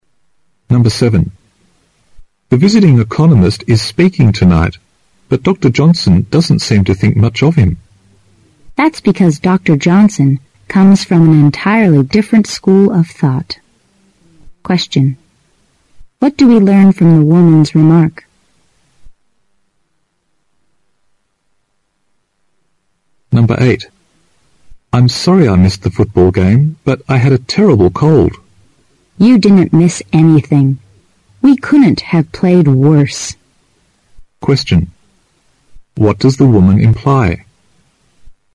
新编六级听力短对话每日2题 第136期